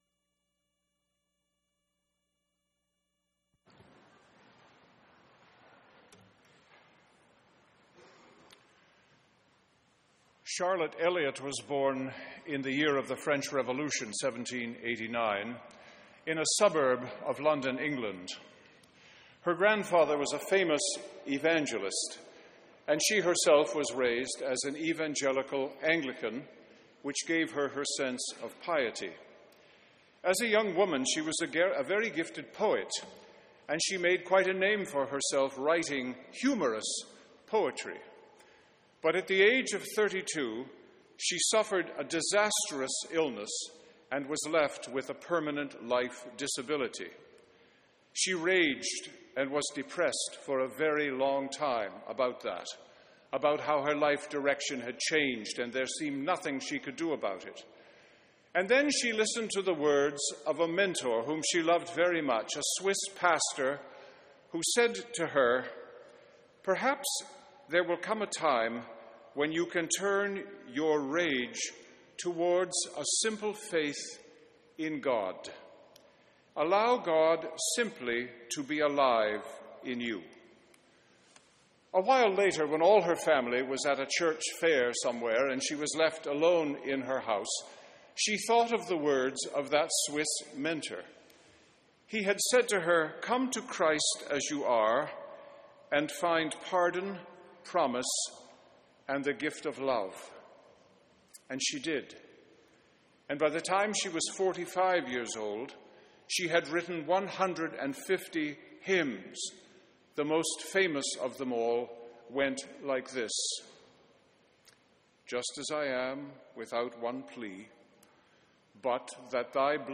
Sermons & Livestreams | Metropolitan United Church